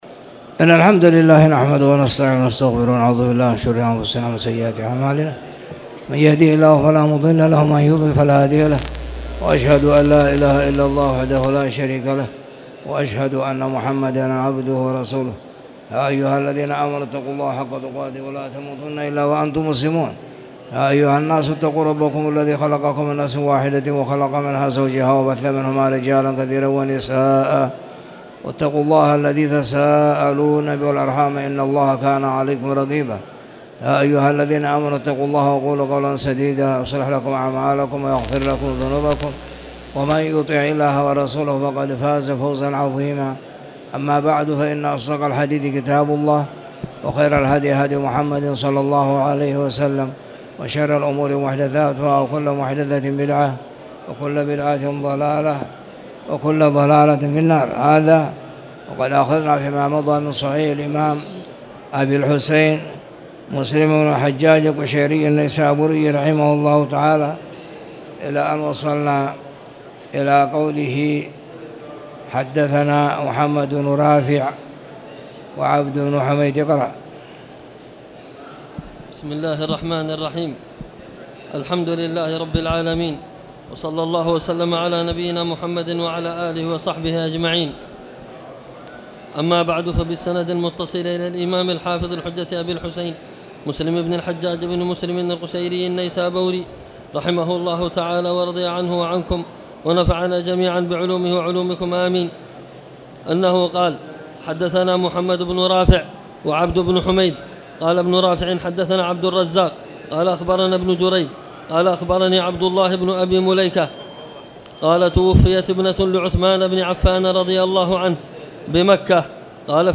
تاريخ النشر ١٦ ذو الحجة ١٤٣٨ هـ المكان: المسجد الحرام الشيخ